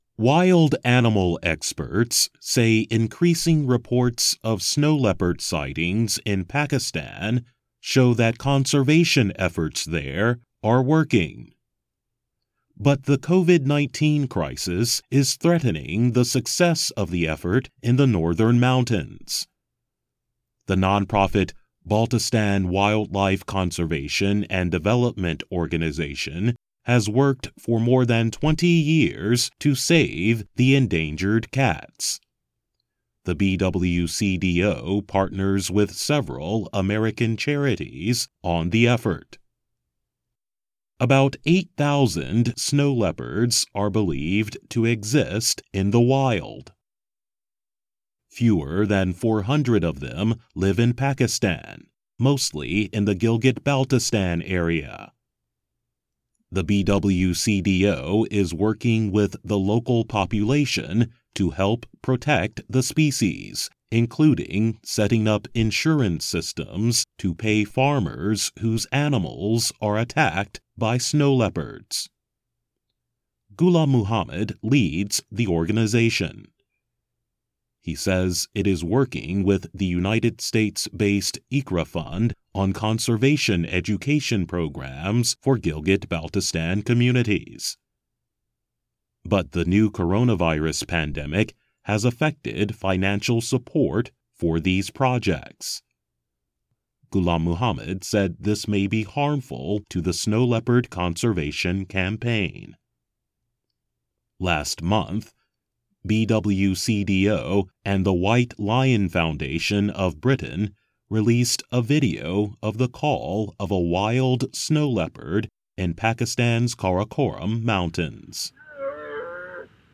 慢速英语:新冠病毒危及雪豹的保护工作